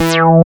75.09 BASS.wav